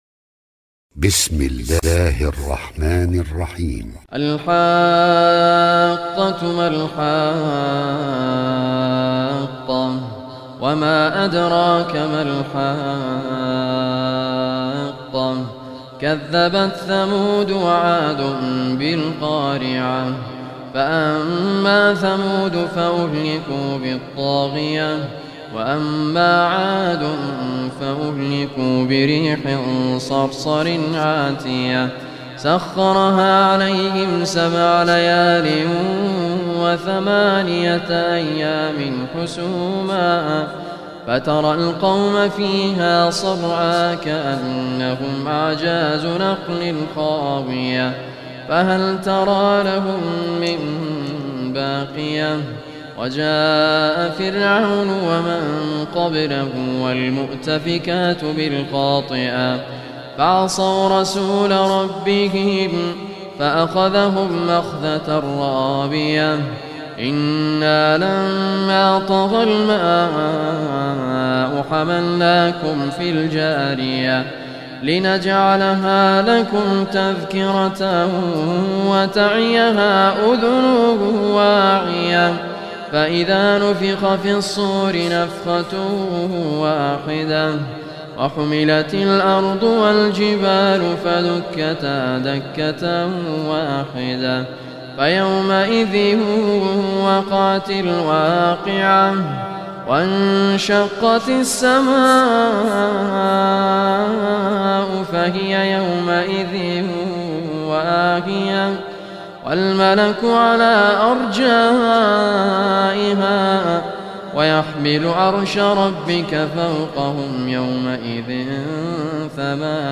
Surah Al-Haqqah Recitation